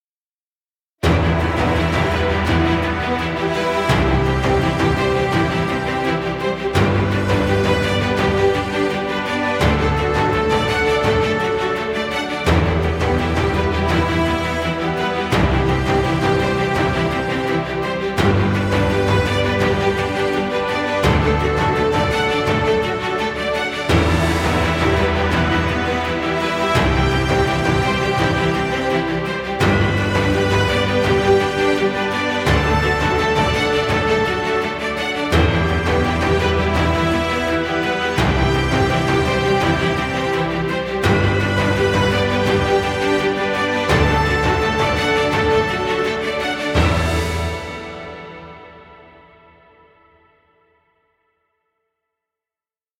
Epic cinematic music.